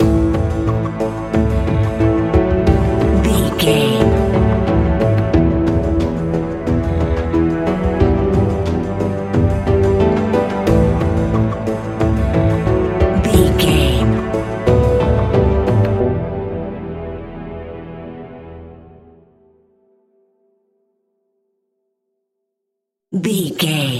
Aeolian/Minor
ominous
dark
eerie
percussion
synthesizer
suspenseful
instrumentals
horror music